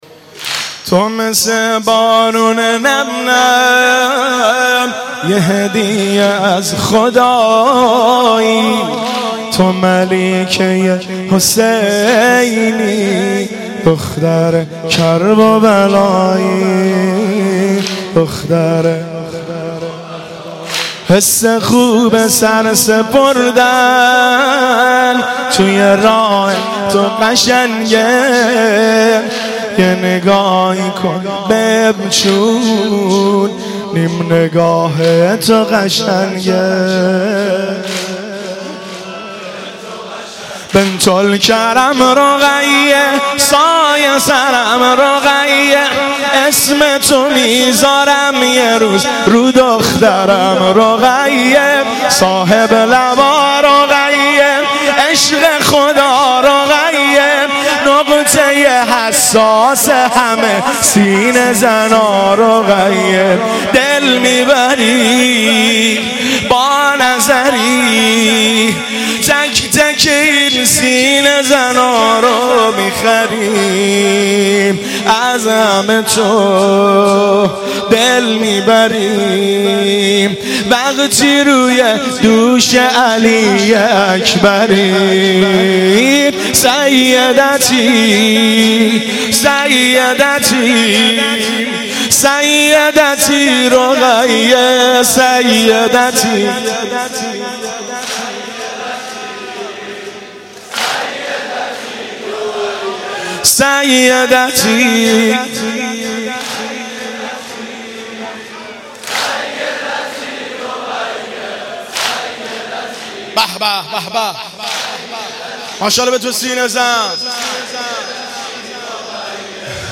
شب سوم محرم - به نام نامیِ حضرت رقیه(س)